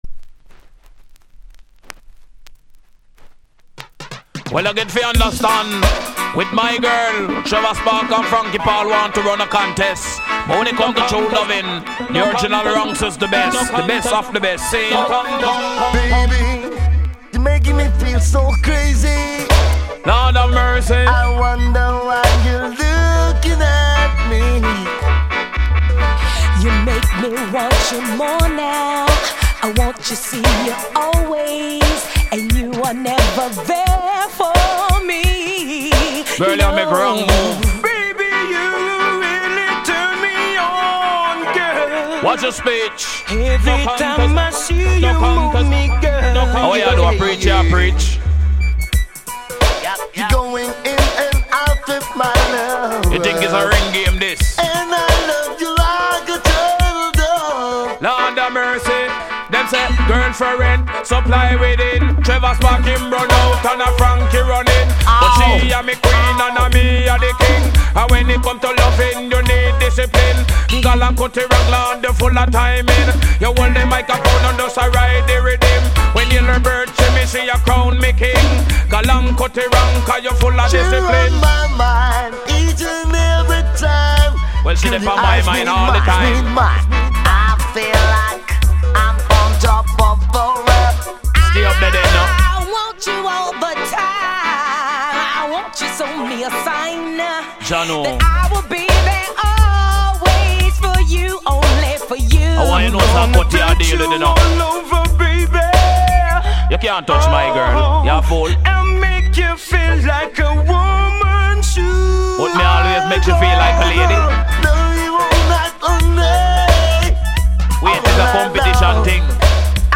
Genre ReggaeAfter90s / Group Vocal